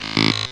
sci-fi_code_fail_11.wav